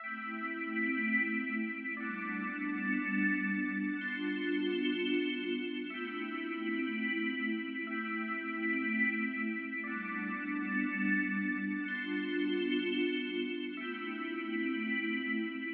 Tag: 155 bpm Ambient Loops Bells Loops 2.08 MB wav Key : A FL Studio